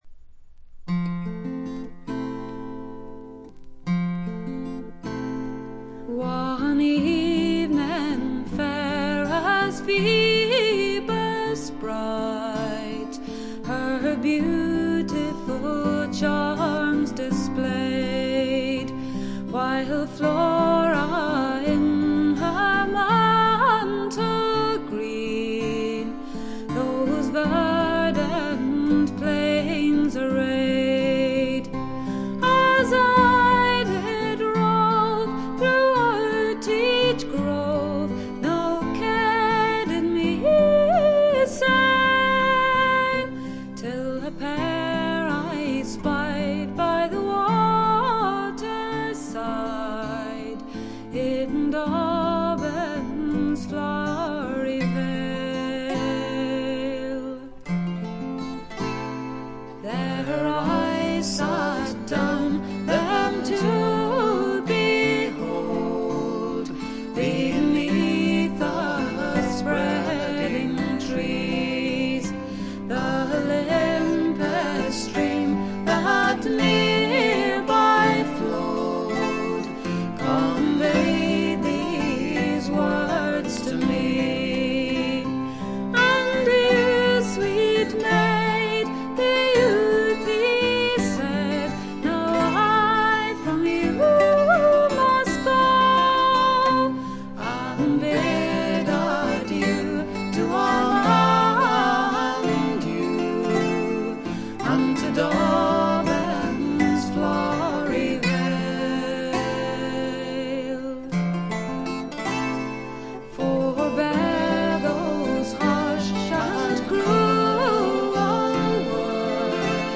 ほとんどノイズ感無し。
アイルランドの女性シンガー
天性のとても美しい声の持ち主であるとともに、歌唱力がまた素晴らしいので、神々しいまでの世界を構築しています。
試聴曲は現品からの取り込み音源です。
Backing Vocals, Bouzouki
Harmonium [Indian]
Lead Vocals, Acoustic Guitar